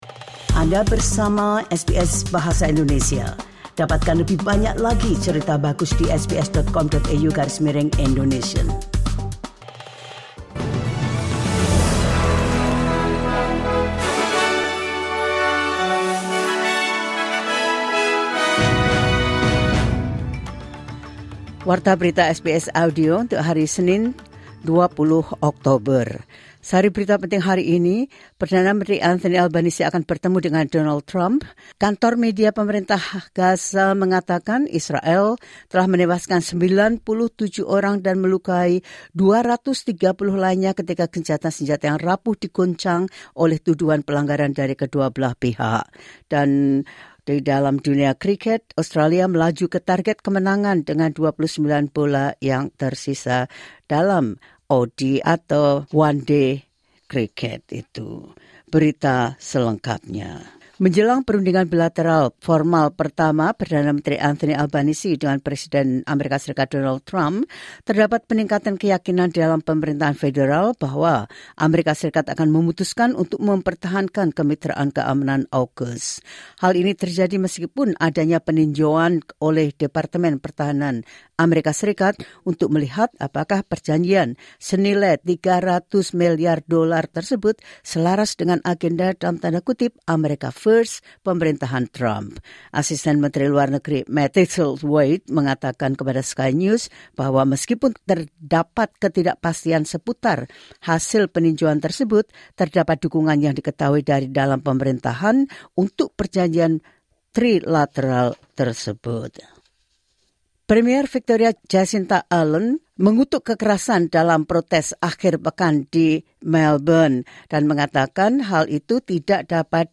The latest news SBS Audio Indonesian Program – 20 October 2025.